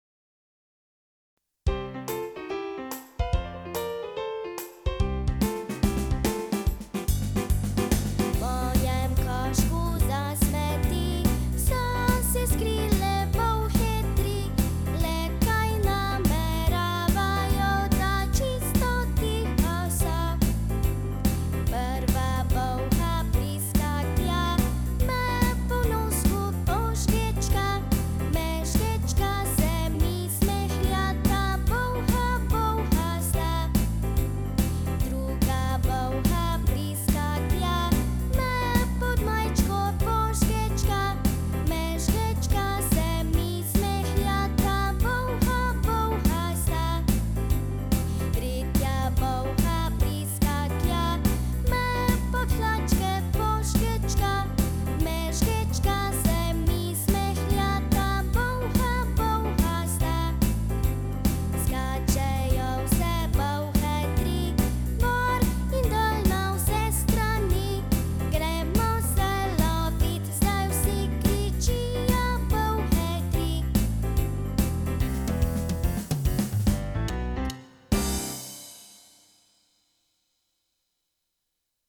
Tri bolhe - Otroška pesmica